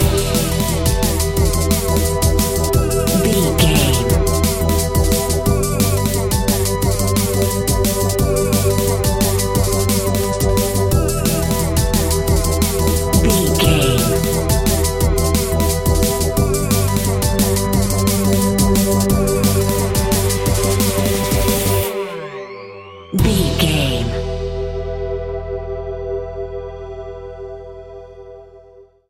Aeolian/Minor
Fast
aggressive
uplifting
hypnotic
dreamy
drum machine
synthesiser
sub bass
synth leads